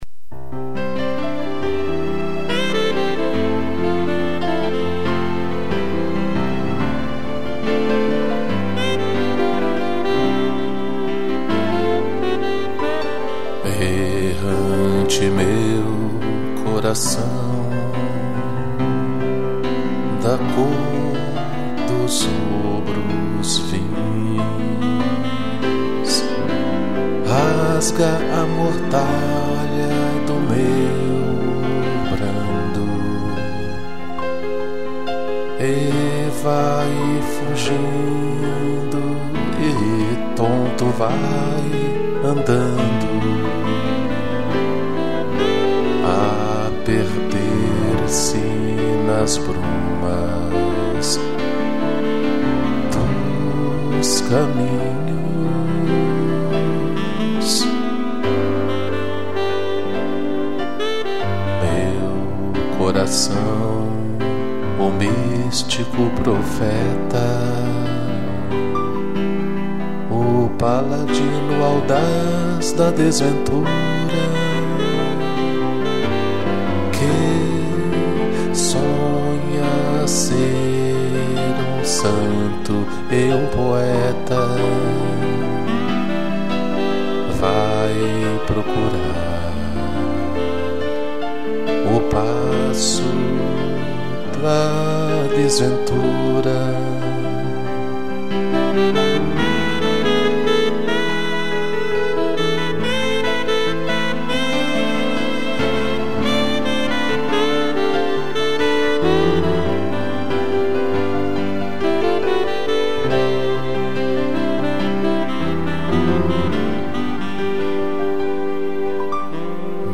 2 pianos, sax e violino